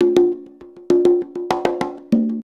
100 CONGAS07.wav